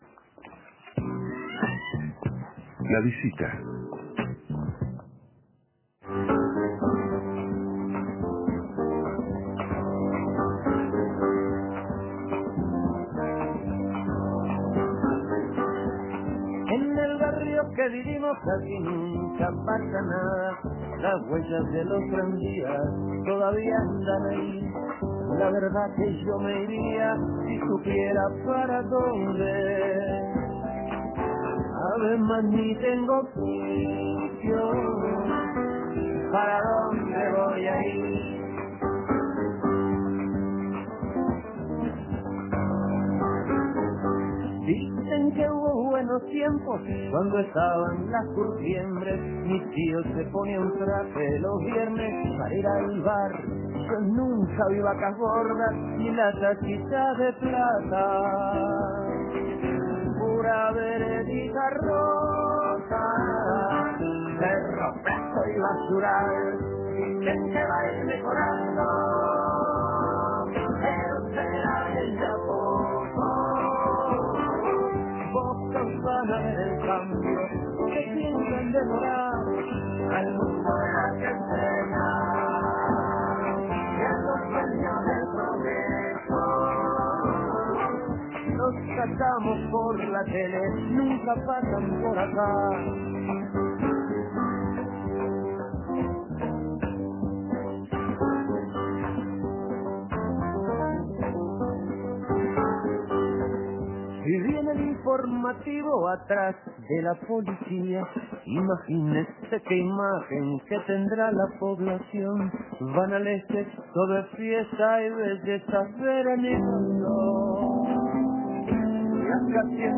Entrevista con el cantautor